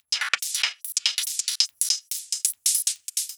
Index of /musicradar/uk-garage-samples/142bpm Lines n Loops/Beats
GA_BeatAFilter142-01.wav